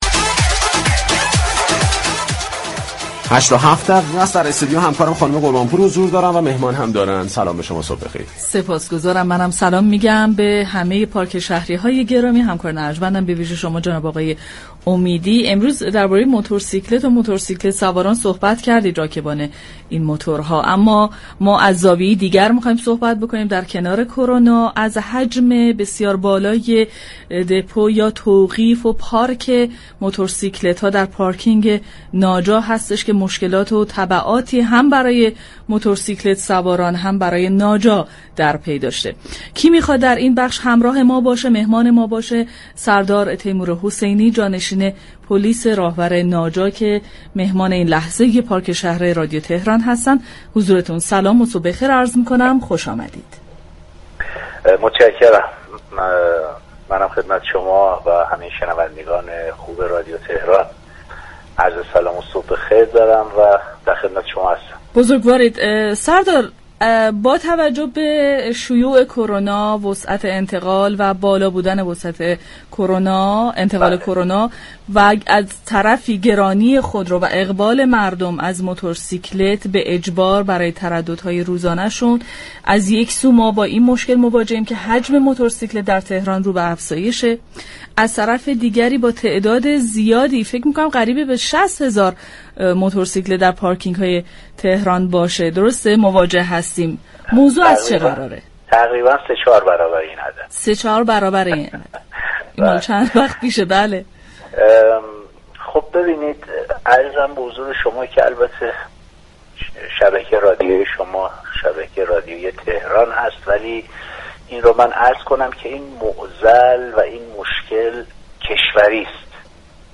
به گزارش پایگاه اطلاع رسانی رادیو تهران، سردار سیدتیمورحسینی در گفتگو با برنامه پارك شهر درباره تعداد بالای موتورسیكلت در كلانشهرها گفت: این مشكل كاملا كشوری است.